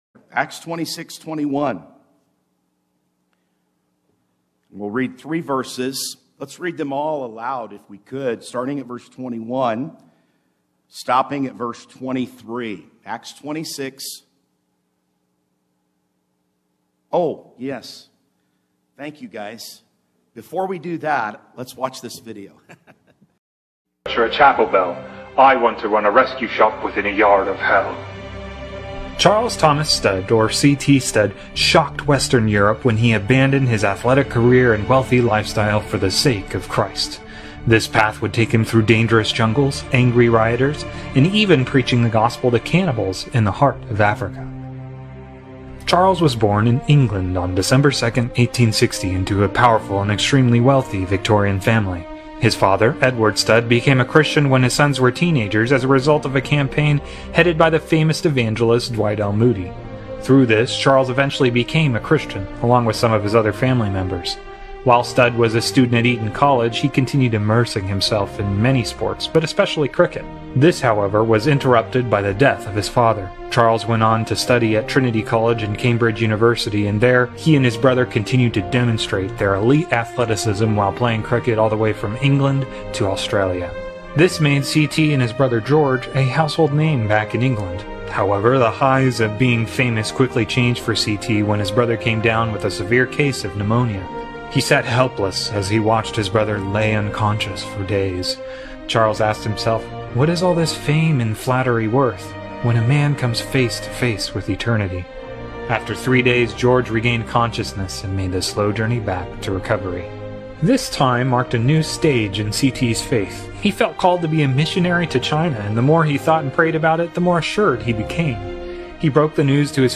Mission Conference 2024